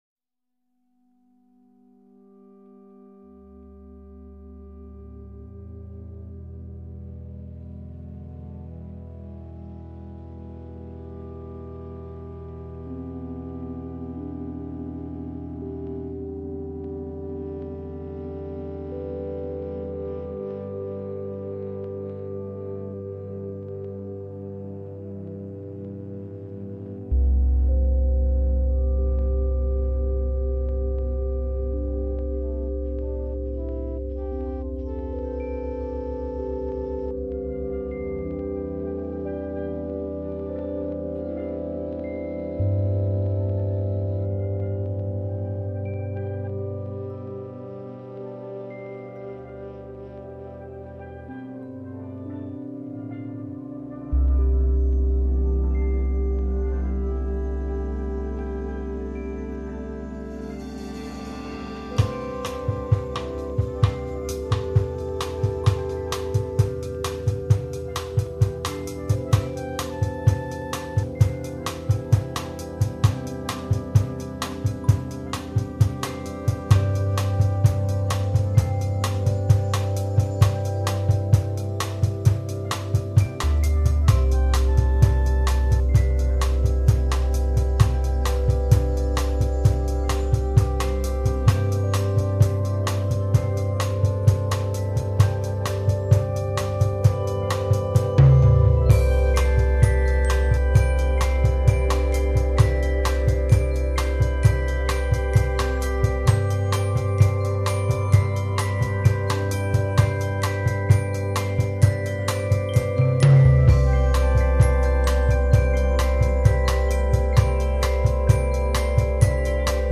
metallophone
おもちゃの鉄琴、メタルフォンが面白かった。
ガジェット系のサウンドで面白い。
パジャマポップと言うんだそうだ。